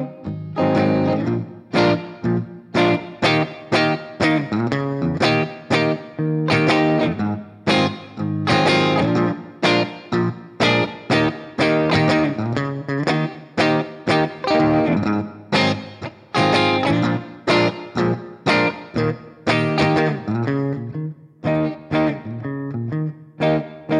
no Backing Vocals Rock 'n' Roll 3:32 Buy £1.50